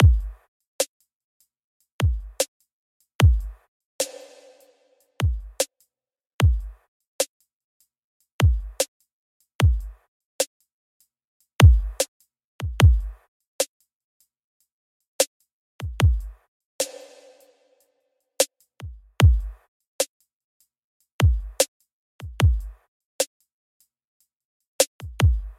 Tag: 75 bpm Hip Hop Loops Drum Loops 4.31 MB wav Key : Unknown